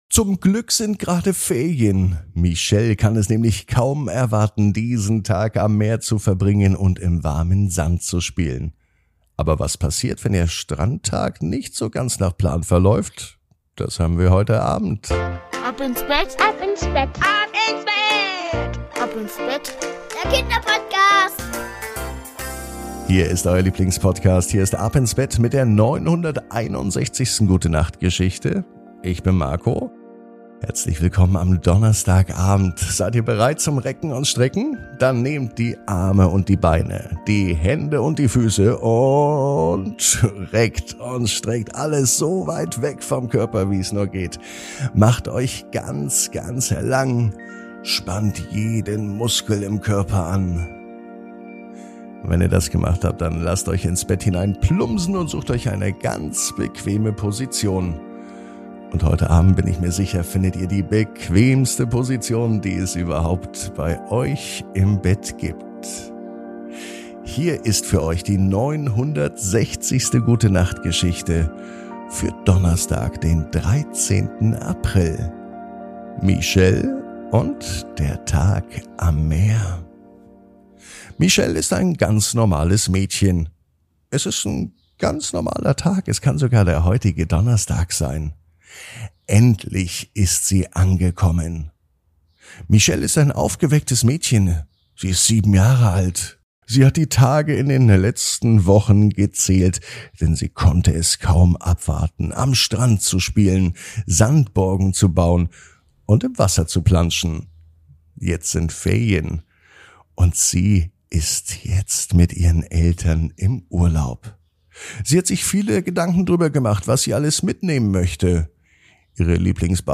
Die Gute Nacht Geschichte für Donnerstag